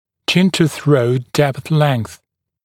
[ˌʧɪntə’θrəut depθ leŋθ][ˌчинту’сроут дэпс лэнс]длина линии перехода от подбородка к шее